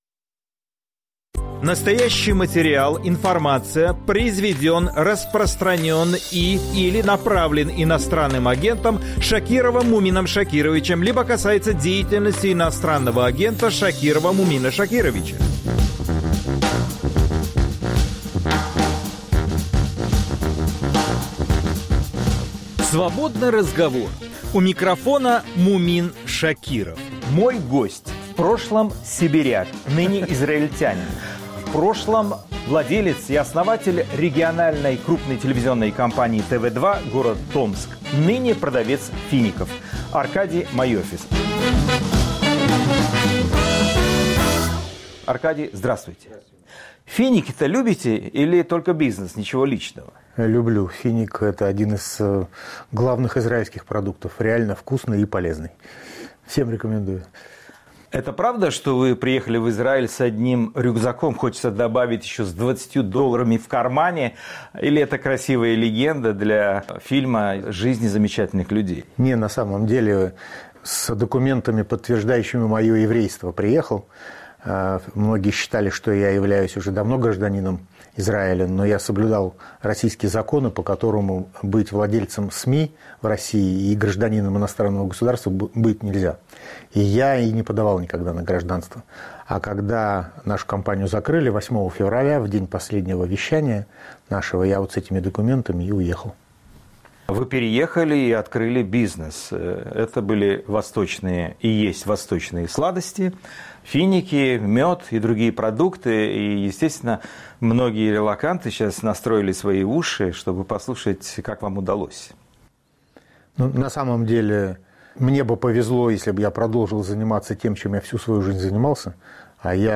Свободный разговор